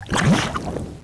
c_slime_atk2.wav